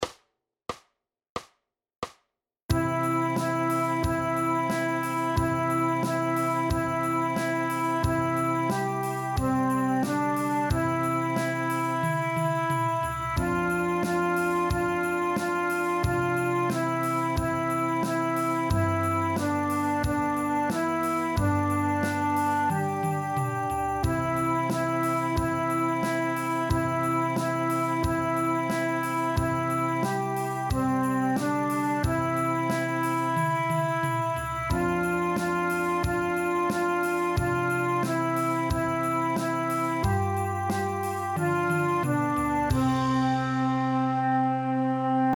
jingle-bells-playalong.mp3